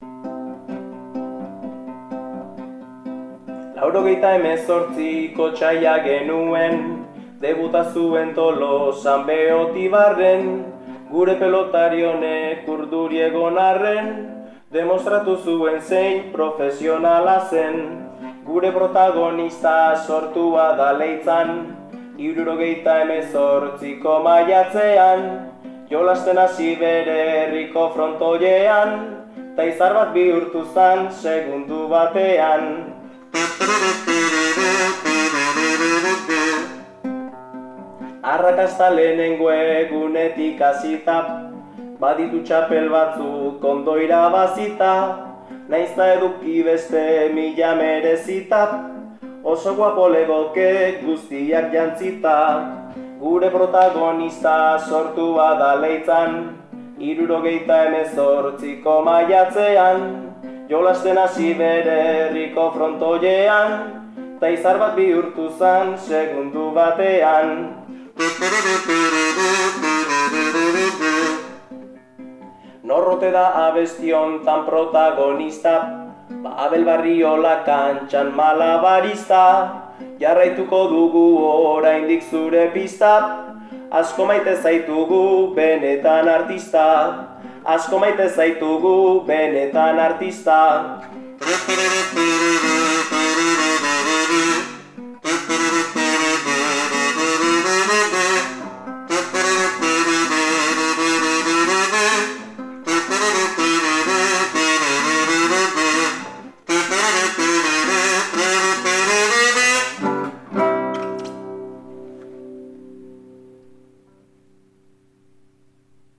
bertso baten bitartez omendu du Abel Barriola.